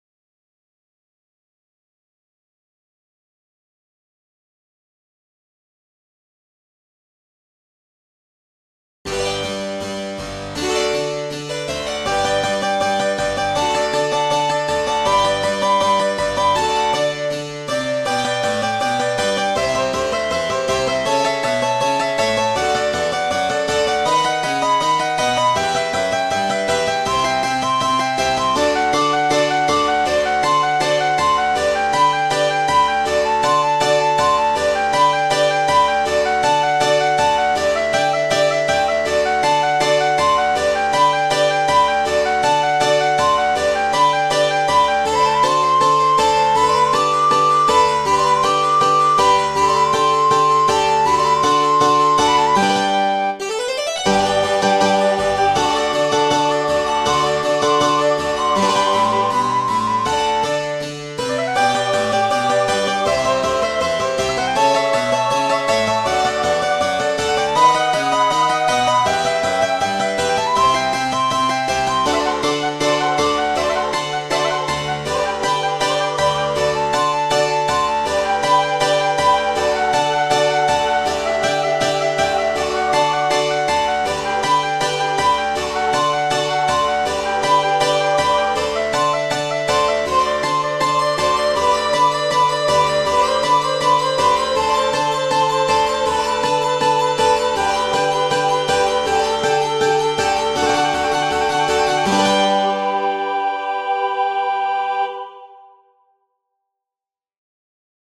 Musica sacra per soprano, mezzosoprano, flauto dolce, mandolino,
The music is inspired by sacred Italian Baroque music, in particular by Vivaldi and Corelli.